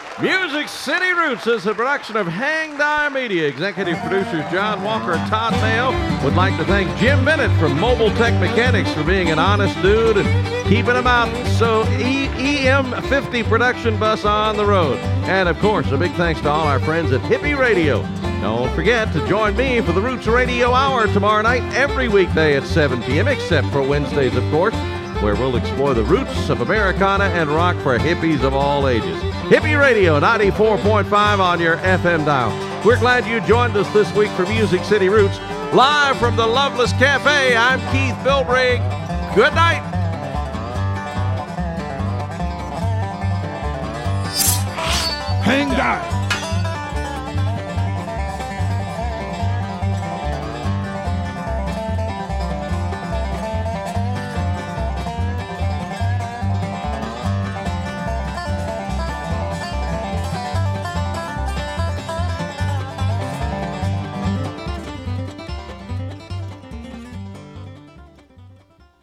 (recorded from webcast)
10. outro (1:09)